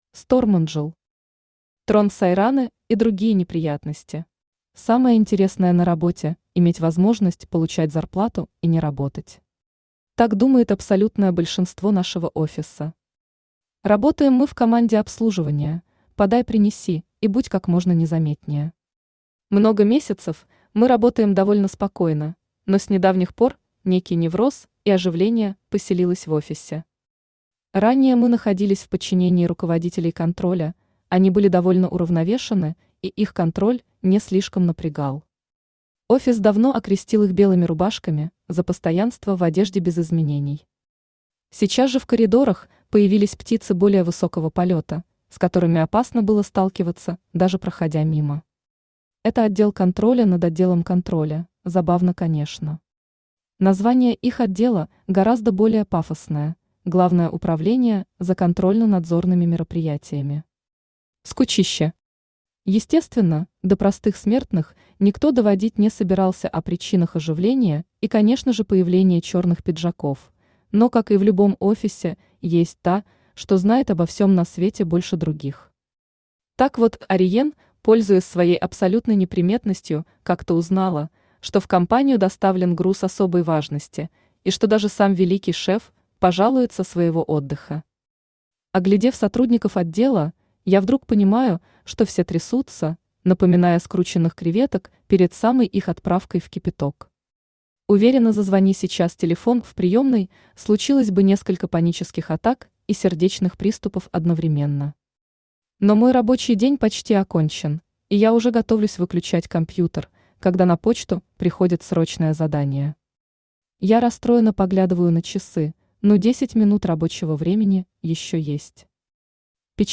Аудиокнига Трон Сайраны и другие неприятности | Библиотека аудиокниг
Aудиокнига Трон Сайраны и другие неприятности Автор Stormangel Читает аудиокнигу Авточтец ЛитРес.